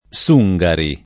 Sungari [ S2jg ari ] top. m. (Cina)